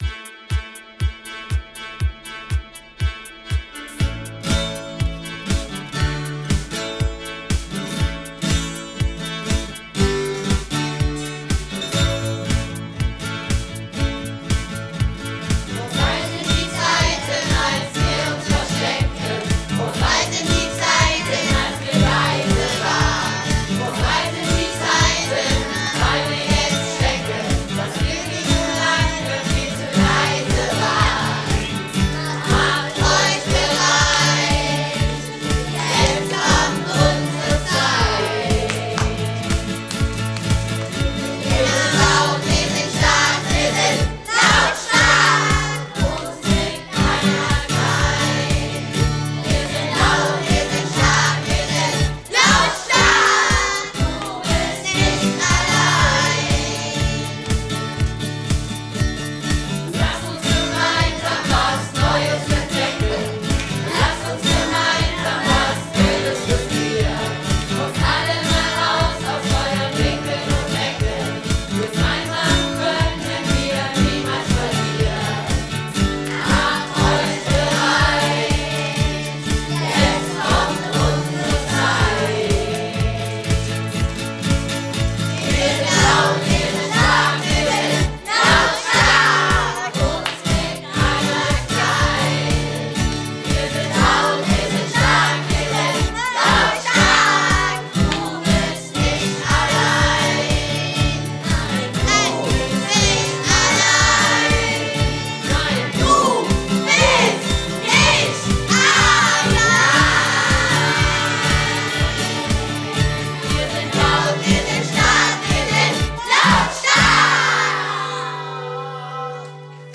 Schulchor LautStark
Die erste Aufnahme unseres Schulchors "LautStark".